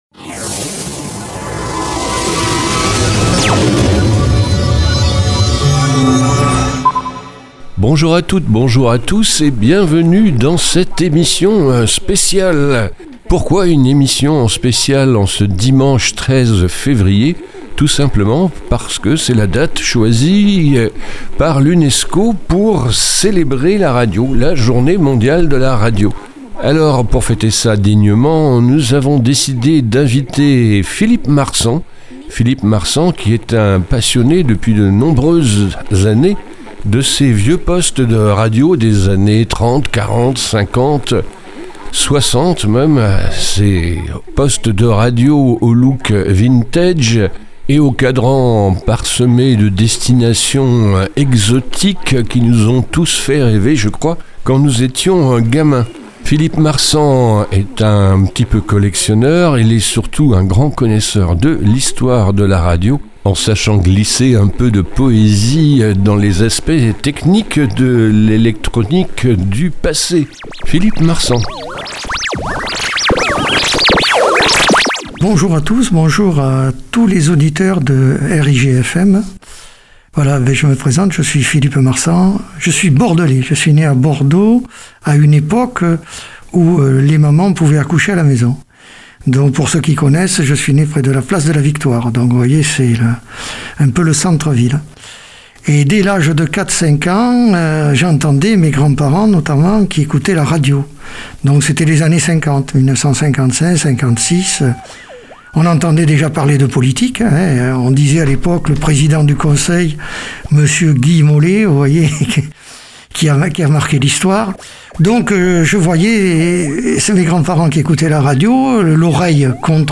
Interview au sujet de la radio... comme on peut!
Bonsoir à tous, La journée mondiale de la radio 2022 m’ a été une occasion pour m’exprimer au microphone d’une radio locale associative près de Bordeaux.